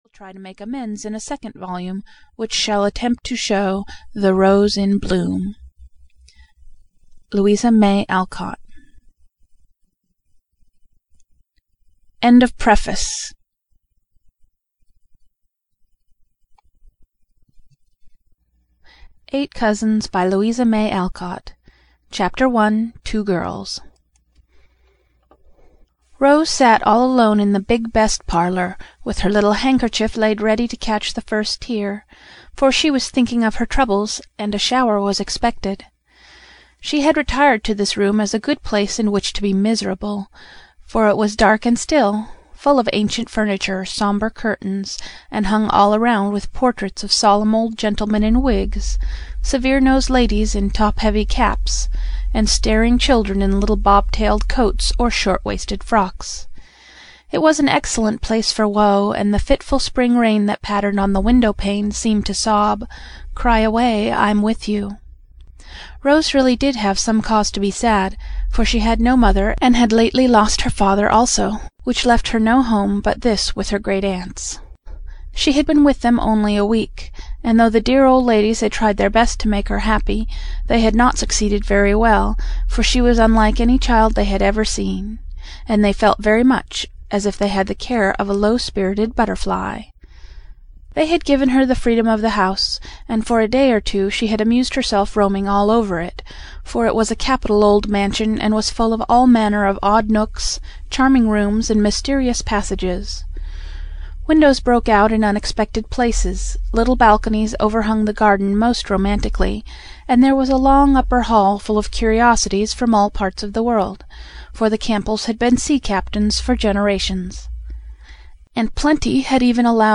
Eight Cousins (EN) audiokniha
Ukázka z knihy